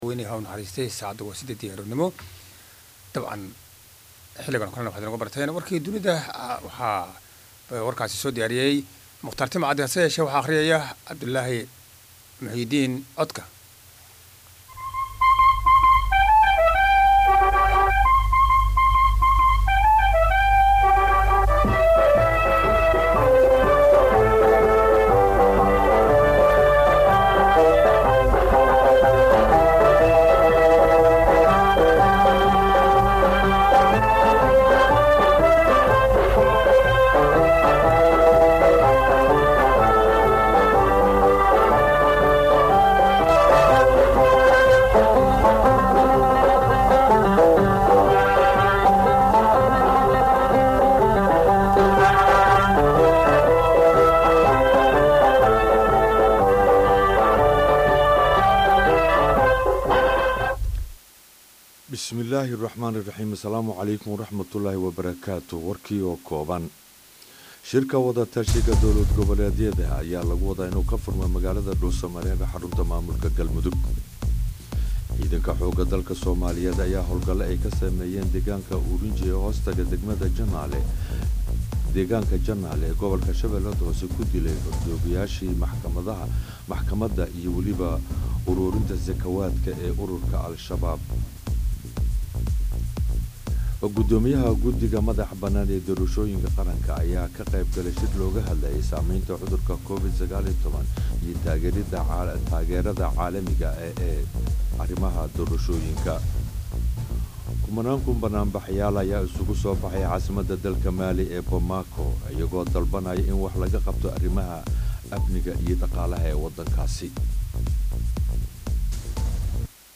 Dhageyso Warka Subax ee Radio Muqdisho